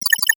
NOTIFICATION_Digital_02_mono.wav